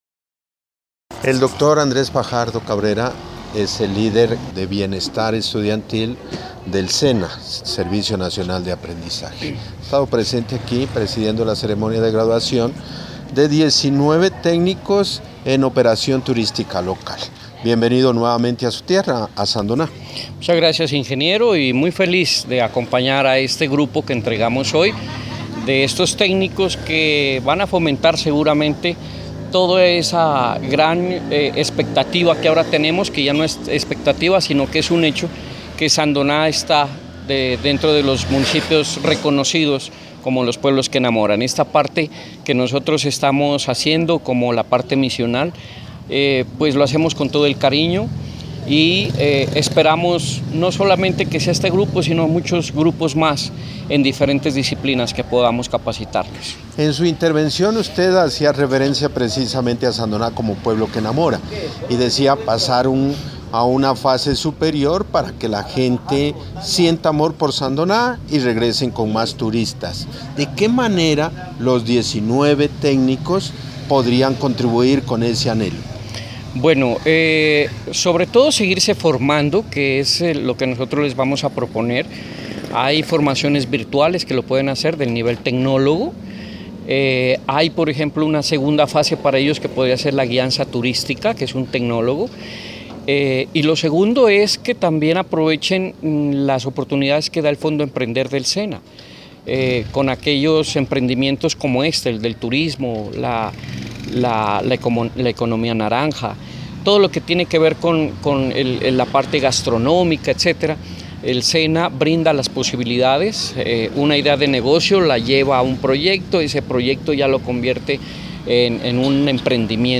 En el salón de eventos del barrio Campo Alegre al sur de Sandoná se desarrolló la ceremonia de graduación de 19 técnicos en operación turística local del Servicio Nacional de Aprendizaje – Sena.